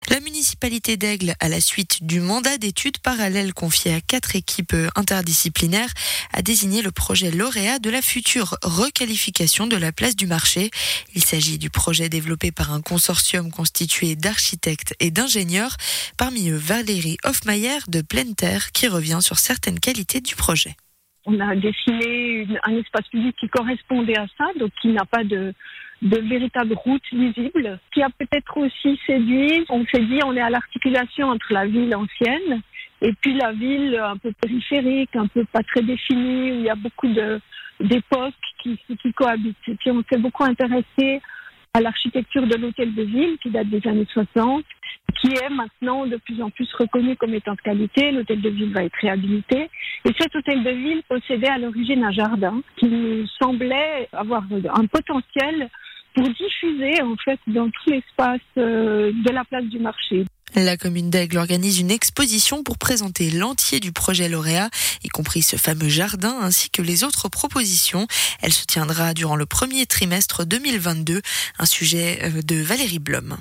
INTERVIEW – Radio Chablais interroge un membre de l'équipe lauréate du concours de réaménagement d’Aigle dont Citec fait partie
Diffusé le 25 novembre sur Radio Chablais